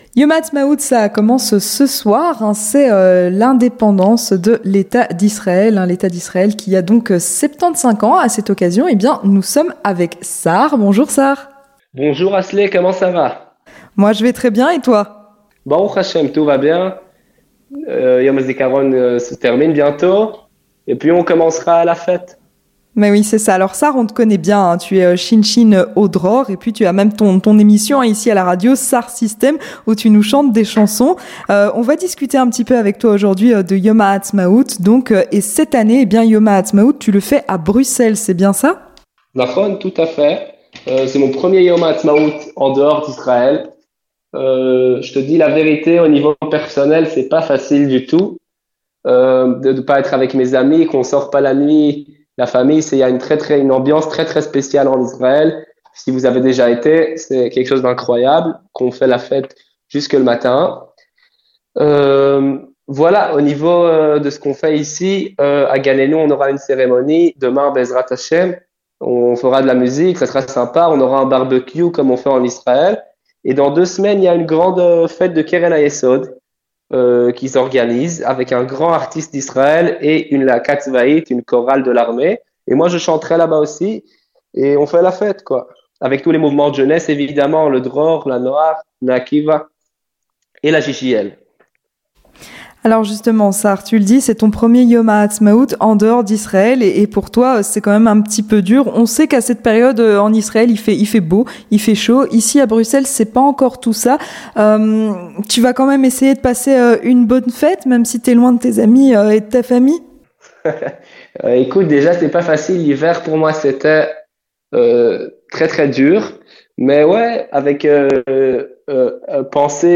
Entretien du 18H - Préparation de Yom Haatsmaout en Belgique et au Dror